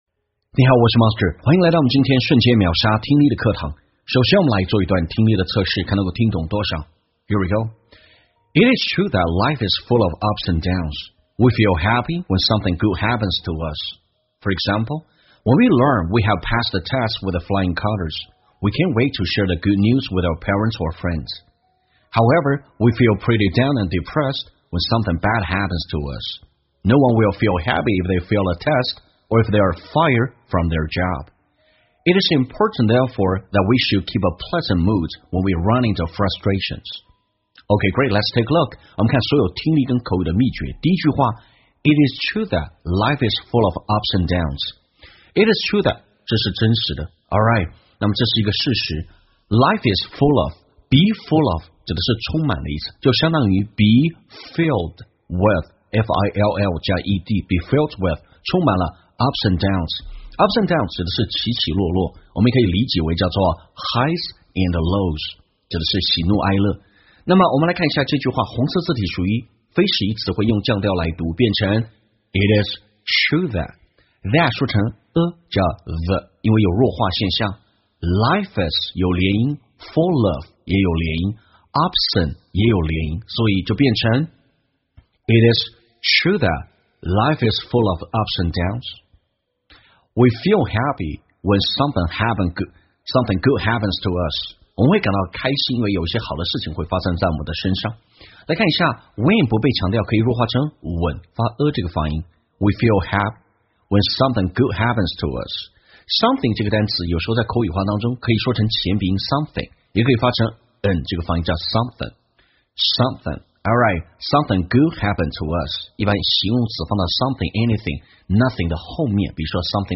在线英语听力室瞬间秒杀听力 第507期:保持好的心情的听力文件下载,栏目通过对几个小短句的断句停顿、语音语调连读分析，帮你掌握地道英语的发音特点，让你的朗读更流畅自然。